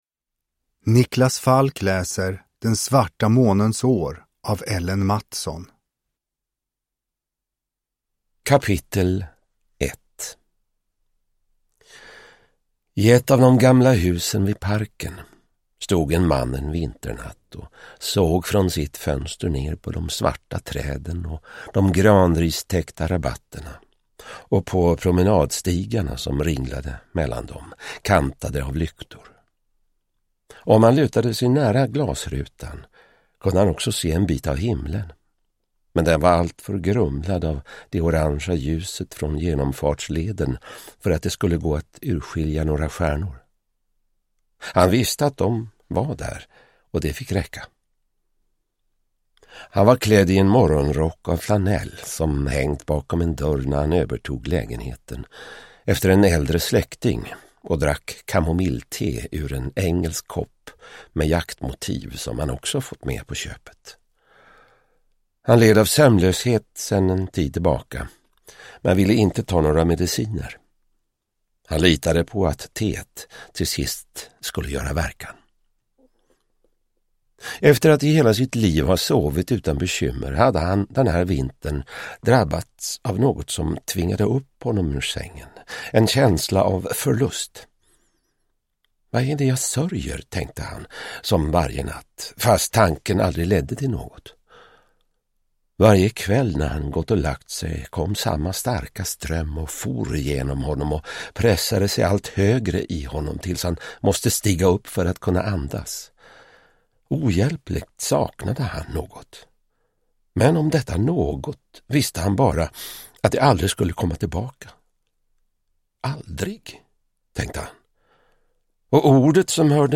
Den svarta månens år – Ljudbok – Laddas ner